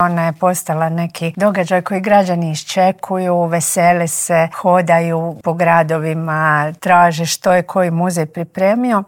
Nacionalni čitalački izazov "15 po 15 - cijela Hrvatska čita djeci" polučio je odlične rezultate, otkrila je u Intervjuu tjedna Media servisa ministrica kulture i medija Nina Obuljen Koržinek.